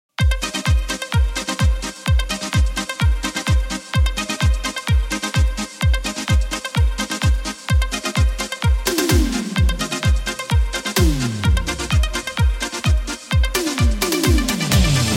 Помогите найти звук отсечки, которая звучит аккордами.